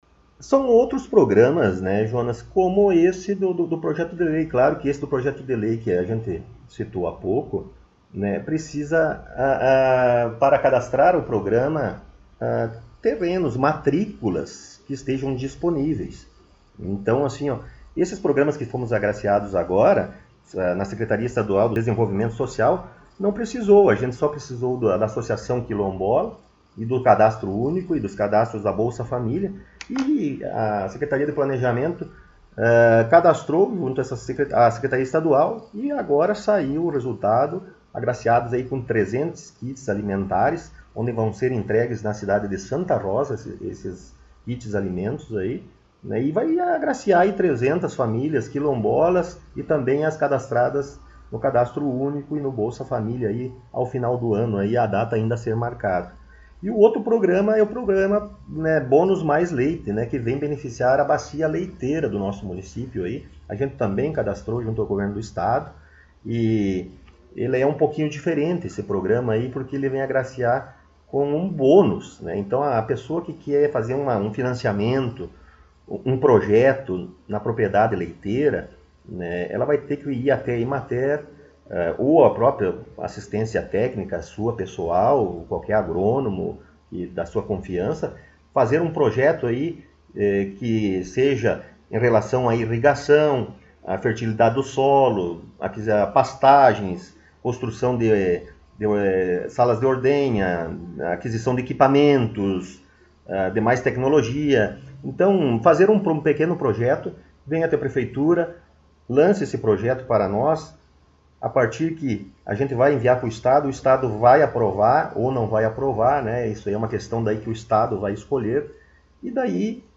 Estivemos mais uma vez em entrevista com o prefeito Rodrigo Sartori em seu gabinete.